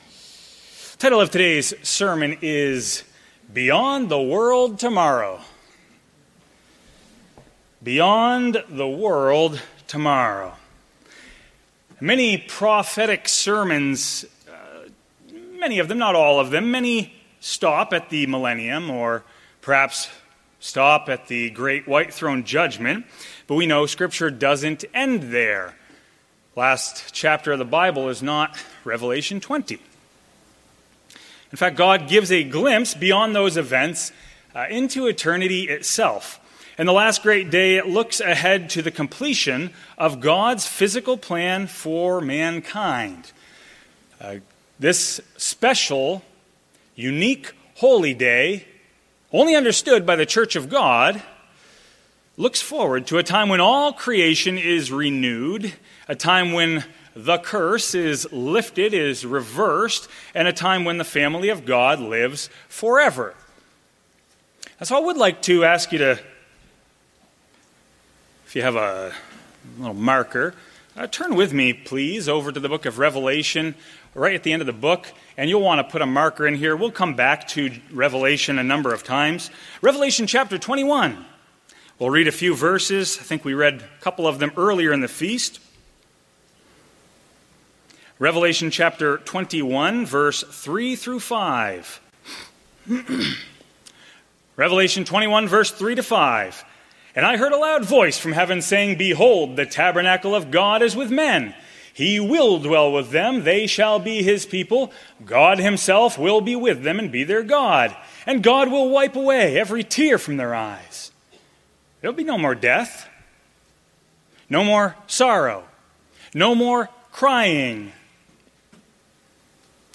Sermons
Given in Rapid City, South Dakota